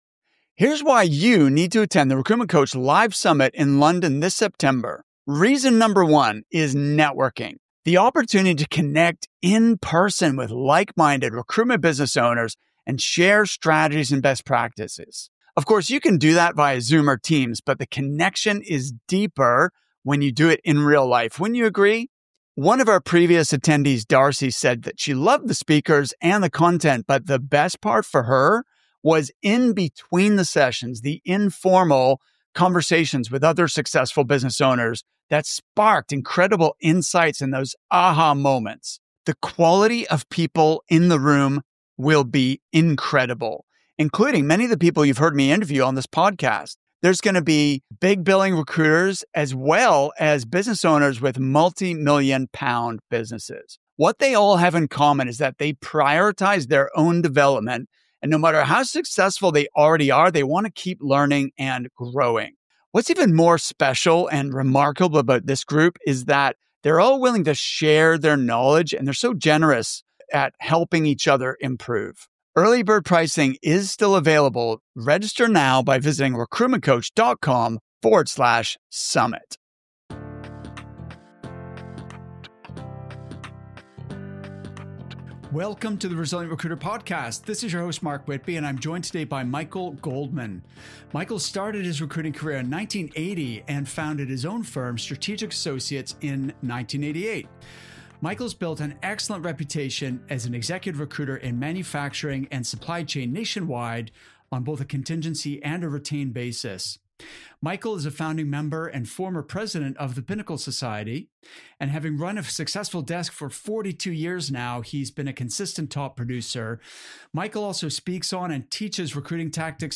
In this interview, he reveals how we as recruiters and business owners can create more value and enjoy greater influence with our clients and candidates.